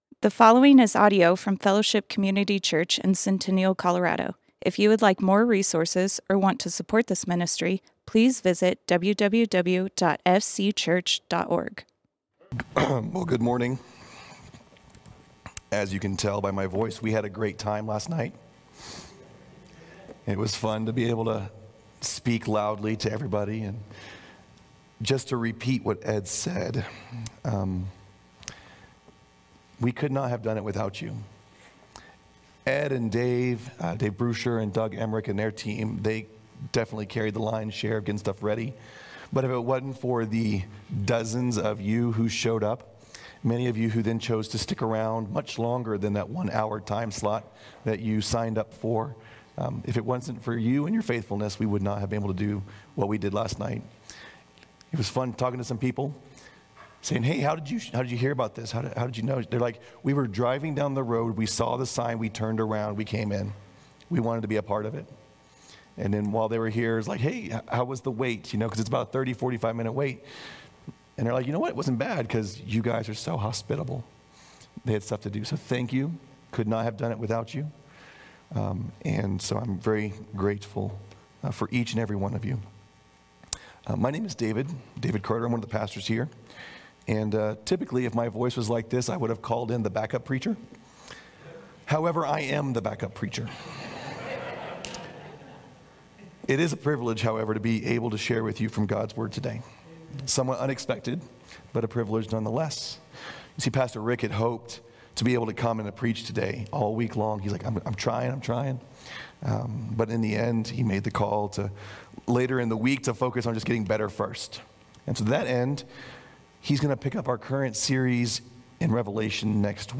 Fellowship Community Church - Sermons A Blue Christmas Play Episode Pause Episode Mute/Unmute Episode Rewind 10 Seconds 1x Fast Forward 30 seconds 00:00 / 31:59 Subscribe Share RSS Feed Share Link Embed